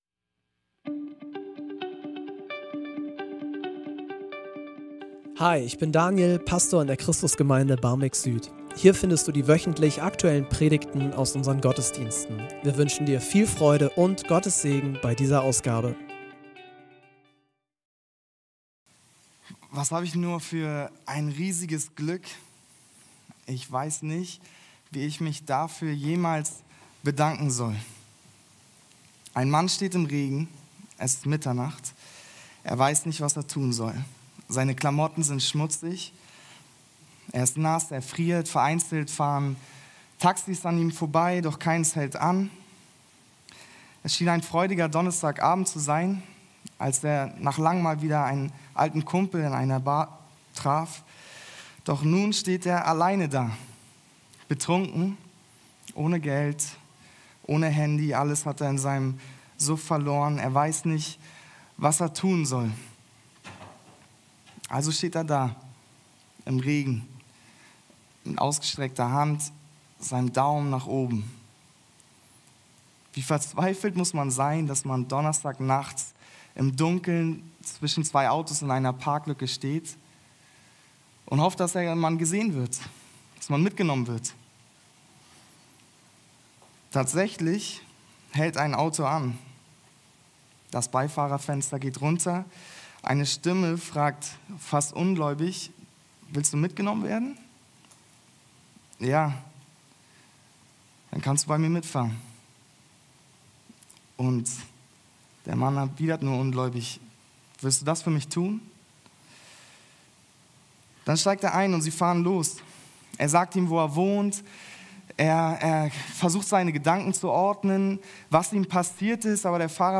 in der vierten Folge der Predigtreihe "Theologie der Erlösung" an Karfreitag.